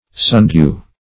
Sundew \Sun"dew`\, n. (Bot.)